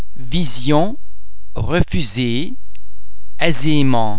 The French [z] sound is normally pronounced [z] as in the English words zero, zebra etc.
·the [s] is pronounced [z] between 2 vowels: